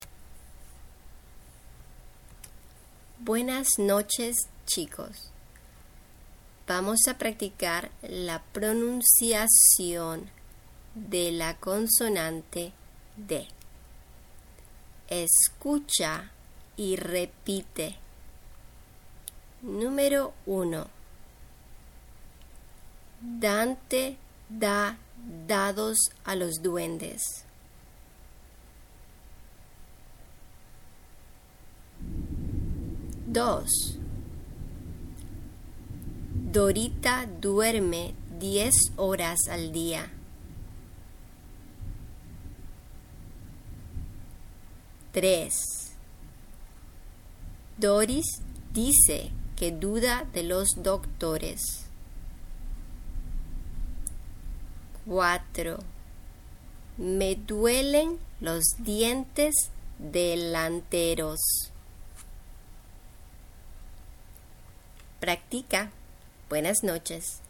Pronunciación "D"
Listen and repeat